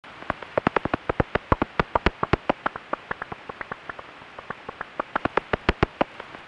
Common pipistrelle at 45kHz, soprano pipistrelle at 55kHz and Nathusius’ pipistrelle at 35kHz. Pipistrelles usually sound like irregular “smacks” that tend to vary in pitch and are at a medium repetition rate.
These are pipistrelle calls as heard on a heterodyne bat detector.
Common pipistrelle –